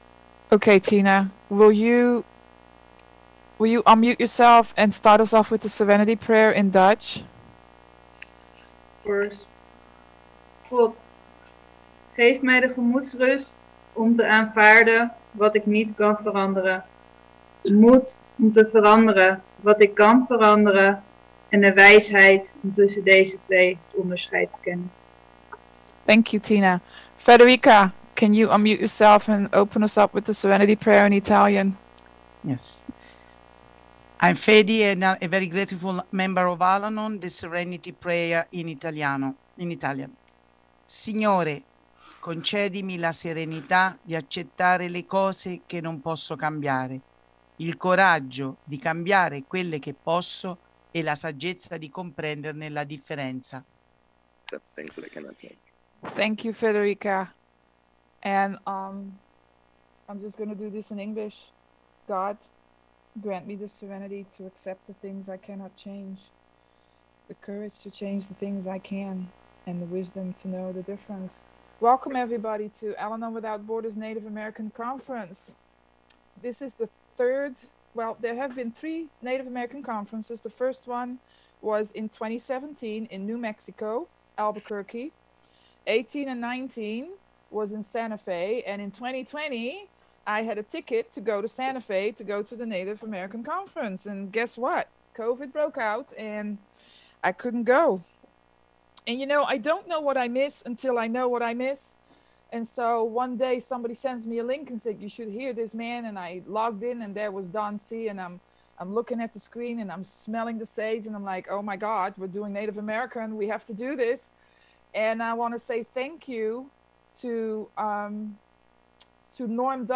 American Indian Conference - AWB Roundup Oct 17-18 - Opening Ceremony